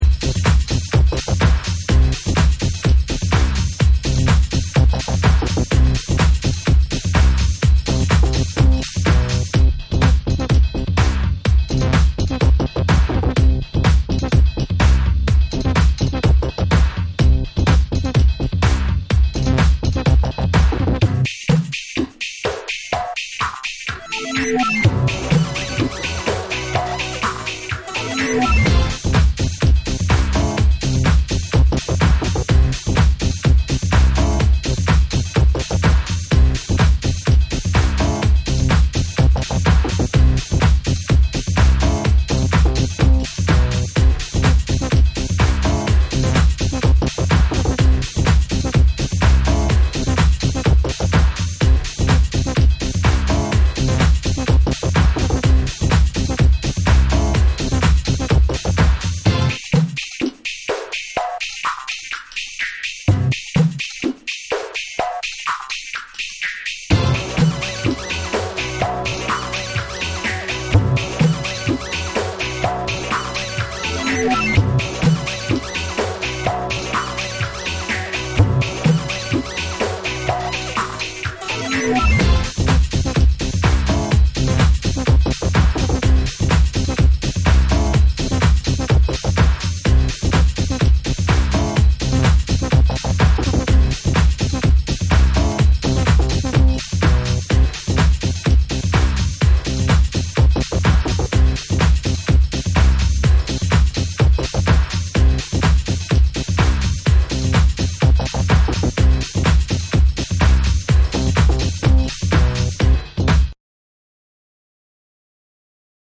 JAPANESE / TECHNO & HOUSE